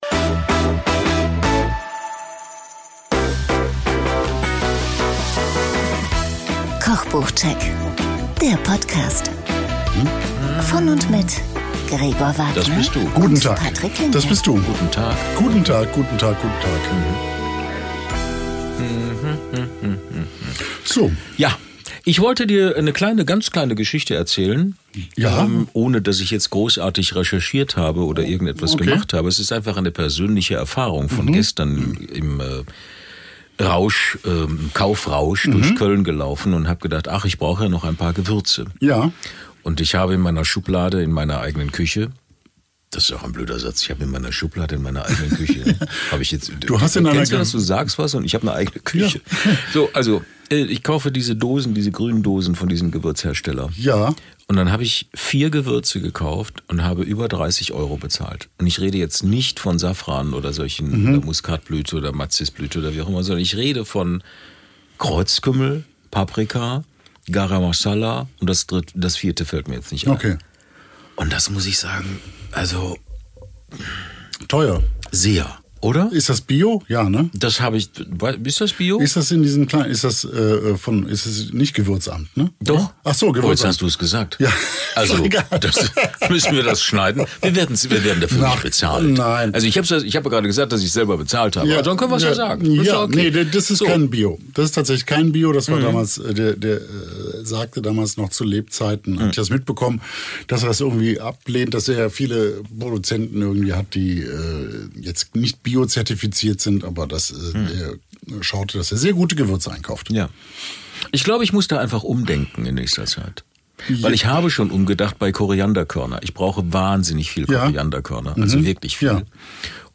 Geniales Gemüse + Vilnius 1:11:01 Play Pause 3h ago 1:11:01 Play Pause Später Spielen Später Spielen Listen Gefällt mir Geliked 1:11:01 Mit den Kochbuch-Besprechungen zu "Geniales Gemüse" und "Vilnius"! Im Interview